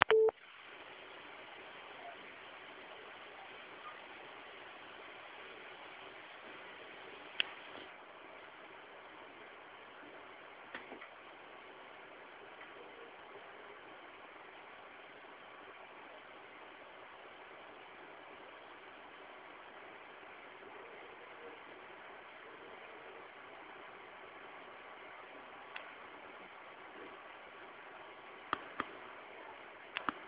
szum mielonego powietrza mimo to jest ono zdecydowanie cichsze niż u Asusa czy EVGA.
galaxy7900gs.wav